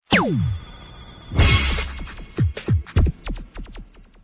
teckno bed